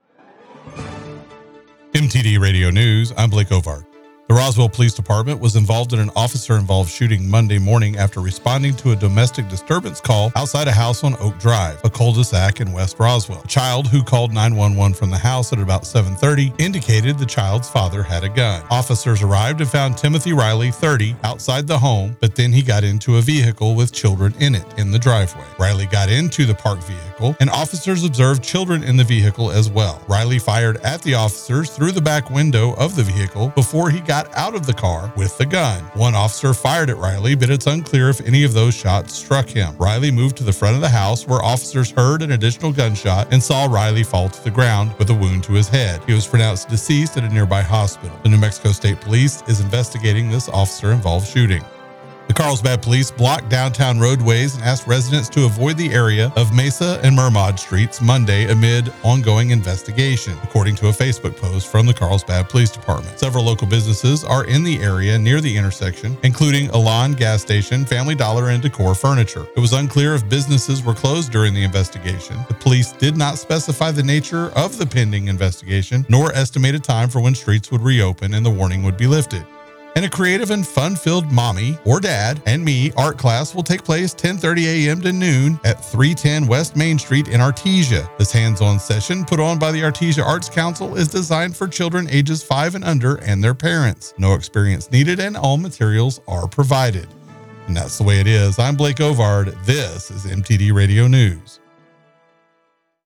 W105 NEWS NEW MEXICO AND WEST TEXAS